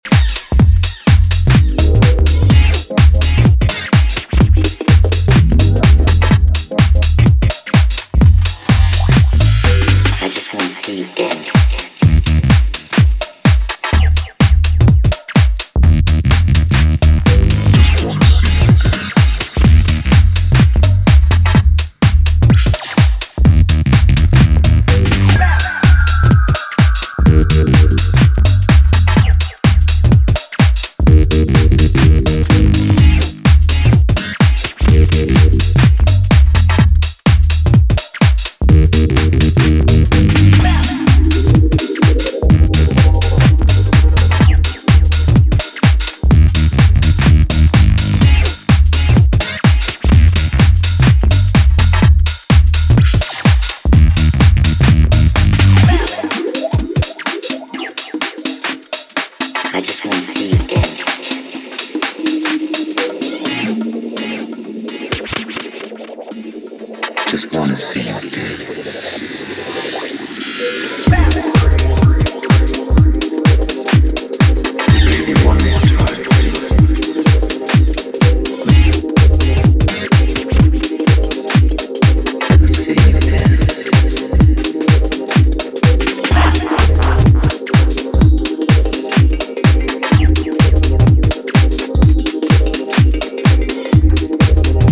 electrifying remix